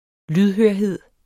lydhørhed substantiv, fælleskøn Bøjning -en Udtale [ ˈlyðhøɐ̯ˌheðˀ ] Betydninger 1. det at være opmærksom, velvillig og god til at opfatte små nuancer, signaler osv.